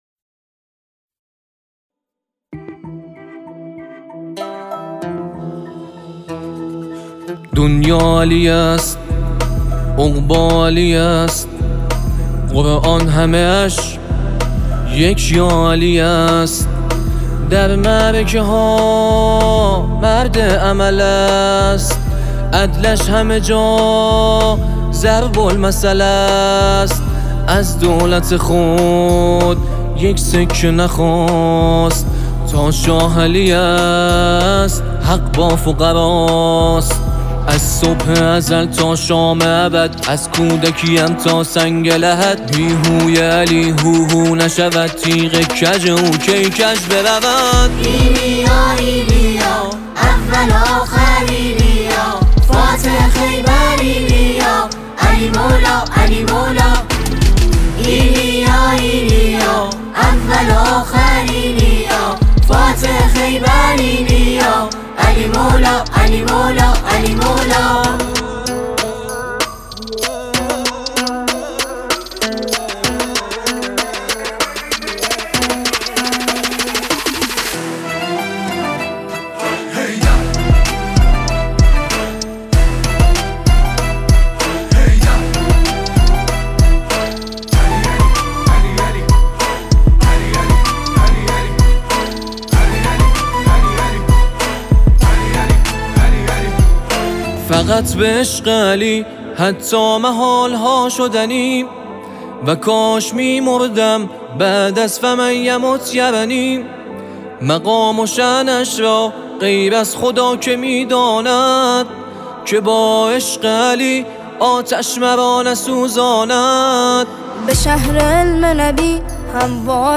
تواشیح و همخوانی نوجوانان
برچسب ها: ویژه برنامه غدیریه ، گروه تواشیح و هم‌خوانی